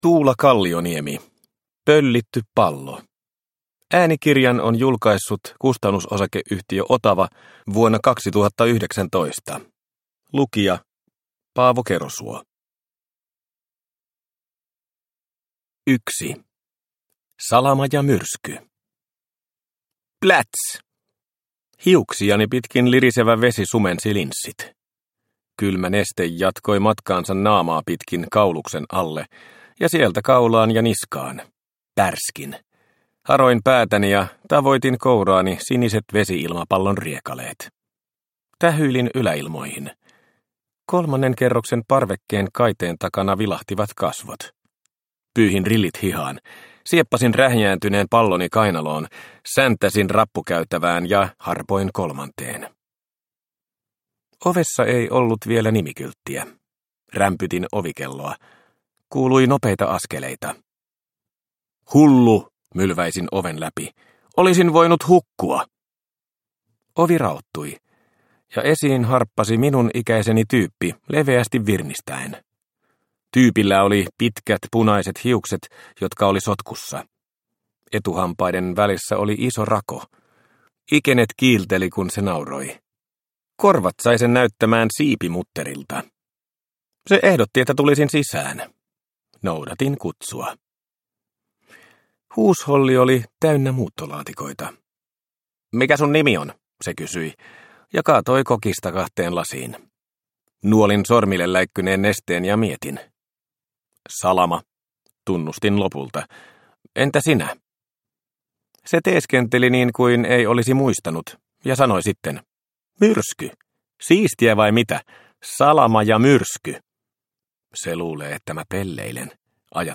Pöllitty pallo – Ljudbok – Laddas ner